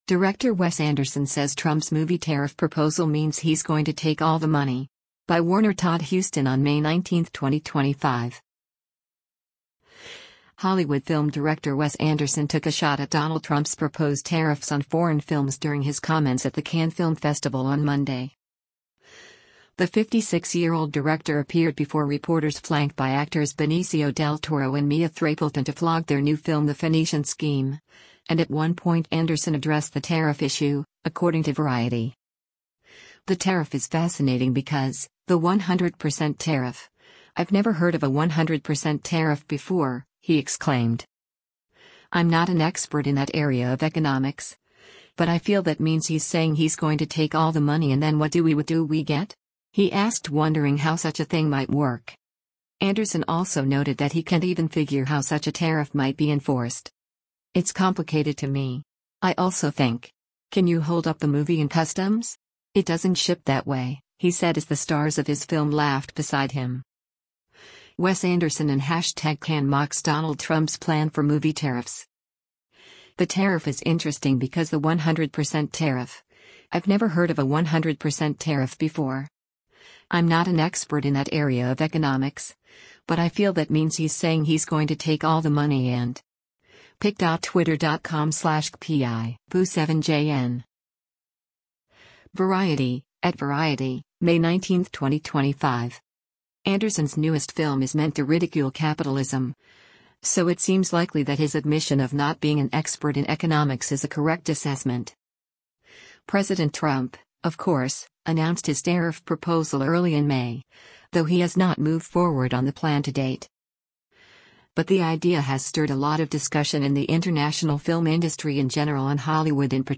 Hollywood film director Wes Anderson took a shot at Donald Trump’s proposed tariffs on foreign films during his comments at the Cannes Film Festival on Monday.
“It’s complicated to me. I also think… Can you hold up the movie in customs? It doesn’t ship that way,” he said as the stars of his film laughed beside him.